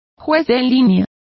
Complete with pronunciation of the translation of linesmen.